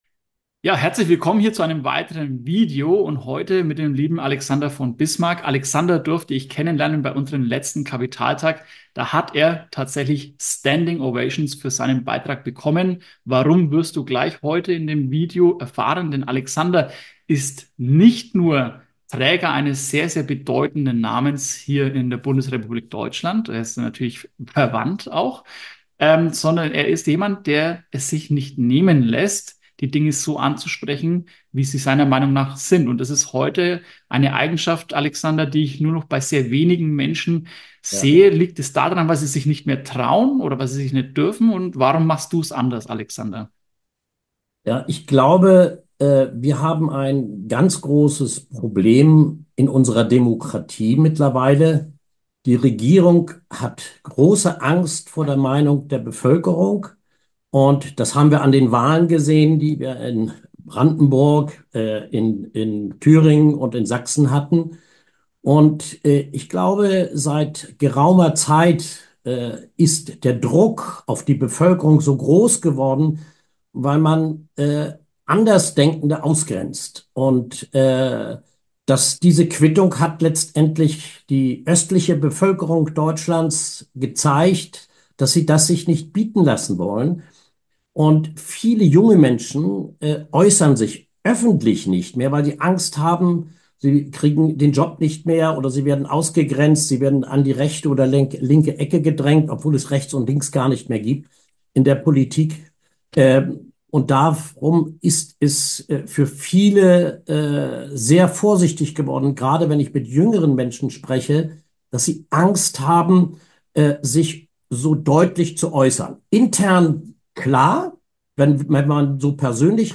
im Gespräch ~ Der Privatinvestor Podcast